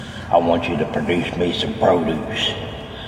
produce me some produce Meme Sound Effect